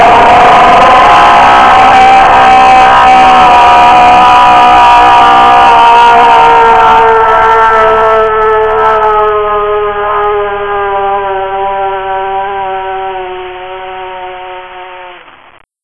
XSCREAM2.wav